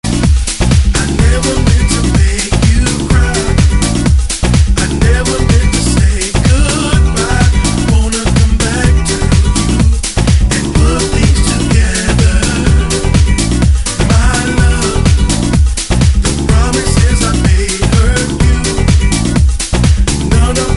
yet another house SONG!!!